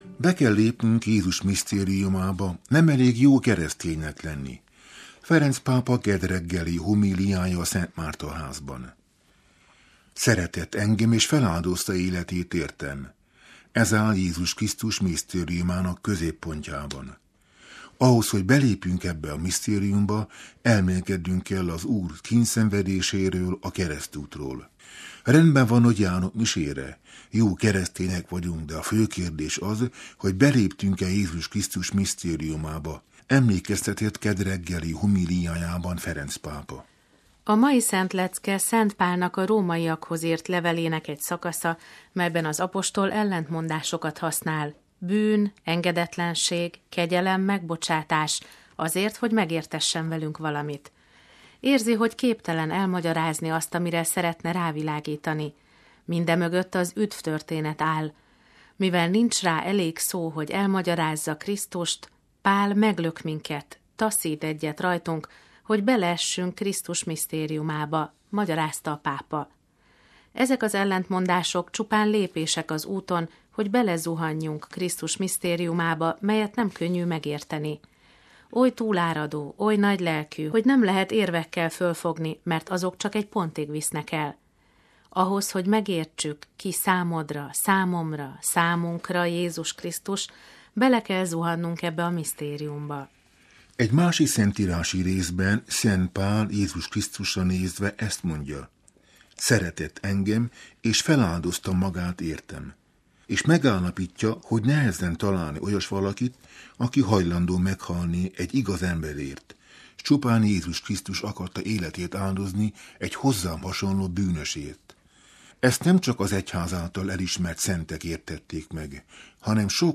Be kell lépnünk Jézus misztériumába: Ferenc pápa kedd reggeli homíliája a Szent Márta-házban